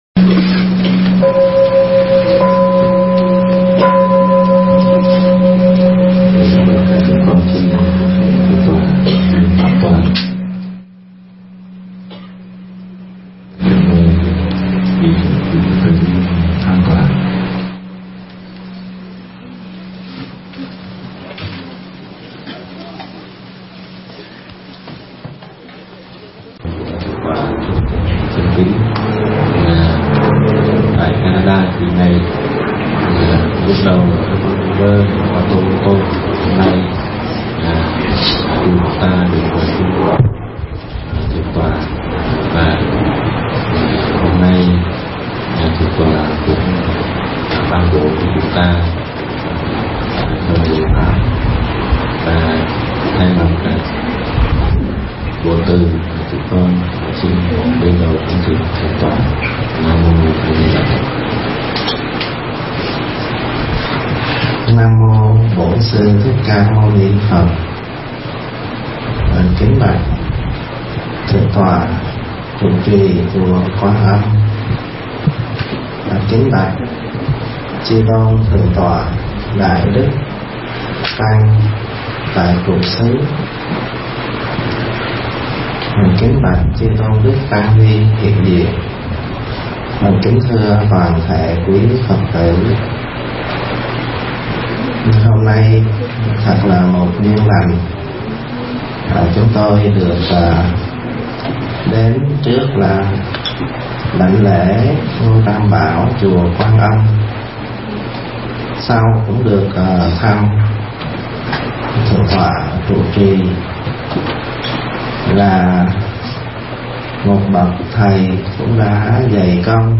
Thuyết Pháp Tại Chùa Quan Âm